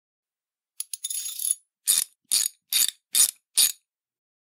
sfx_fixing.mp3